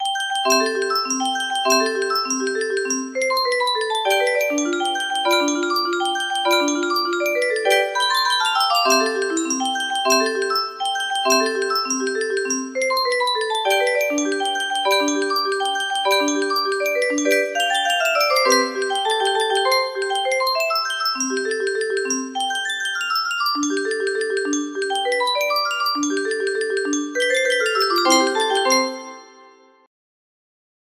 La Cucaracha music box melody